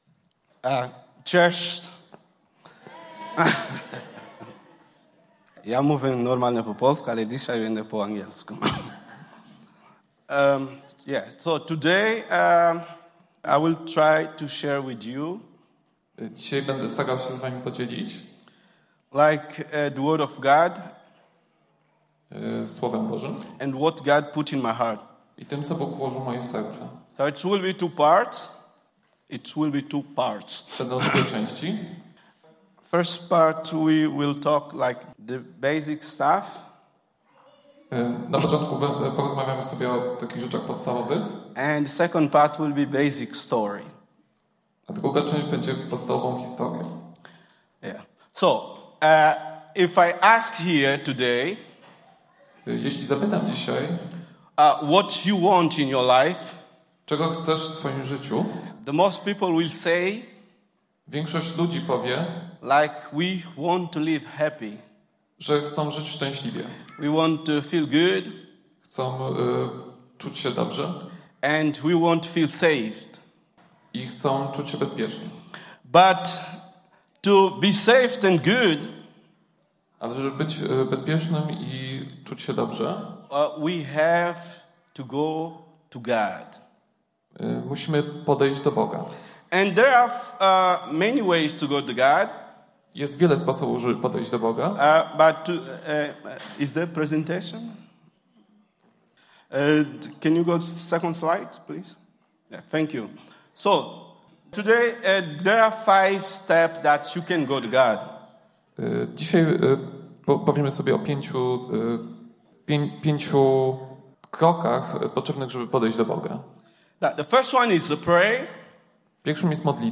Pytania do przemyślenia po kazaniu: